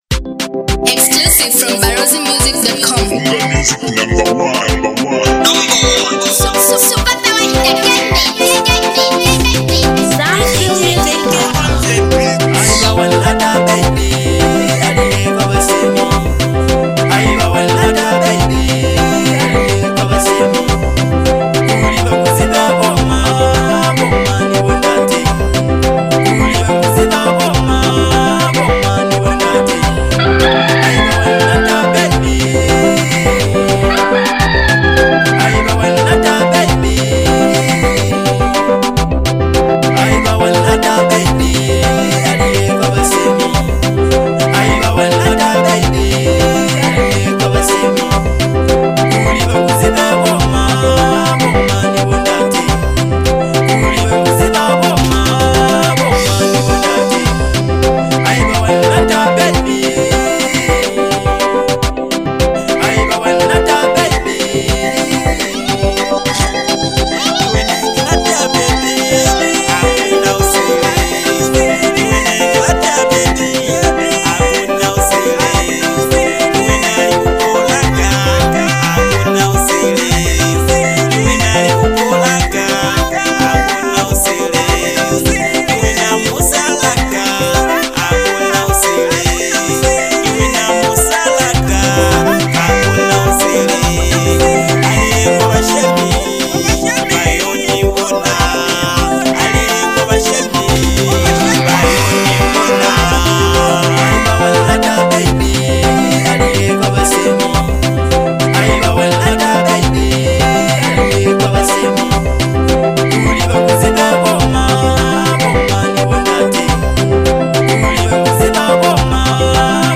a sweet, emotional banger